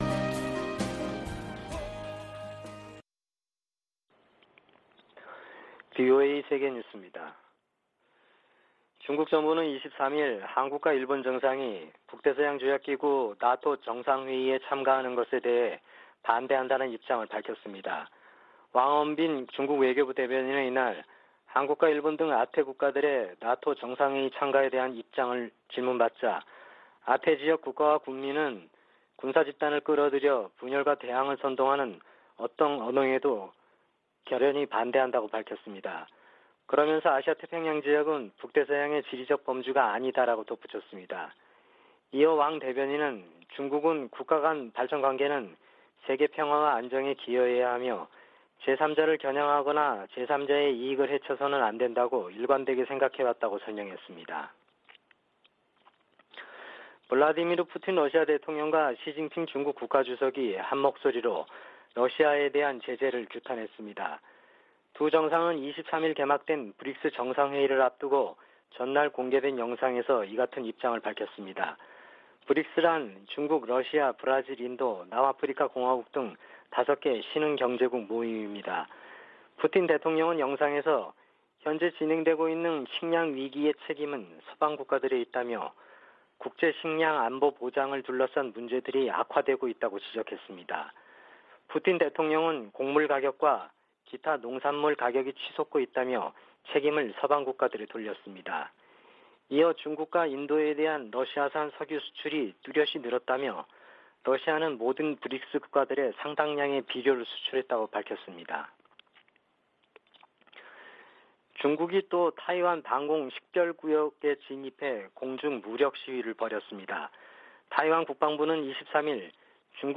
VOA 한국어 아침 뉴스 프로그램 '워싱턴 뉴스 광장' 2022년 6월 24일 방송입니다. 윤석열 한국 대통령이 이달 말 나토 정상회의에 참석할 예정인 가운데, 미 국무부는 한국을 나토의 중요한 파트너라고 언급했습니다. 나토는 사이버·비확산 분야 등 한국과의 협력 관계 증진에 대한 기대감을 나타냈습니다. 미 하원 군사위원회가 강력한 주한미군 유지 중요성을 강조하는 내용 등이 담긴 새 회계연도 국방수권법안을 공개했습니다.